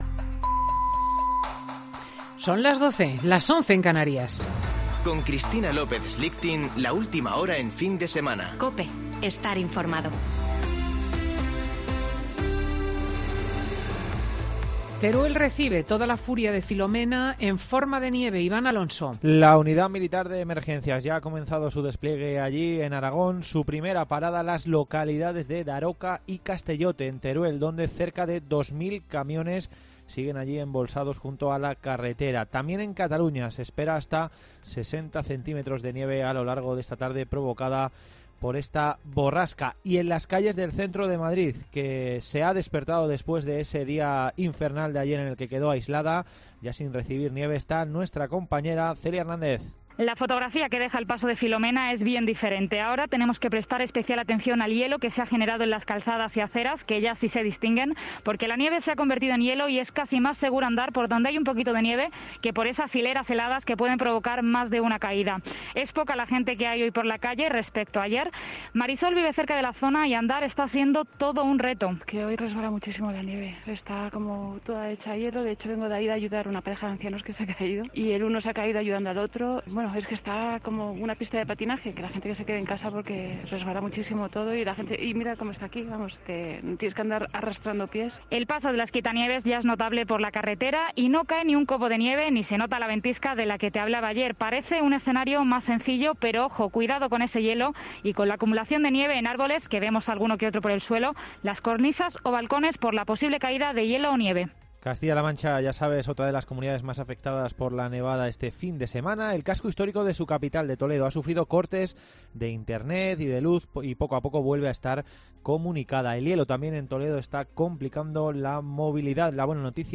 Boletín de noticias COPE del 10 de enero de 2021 a las 12.00 horas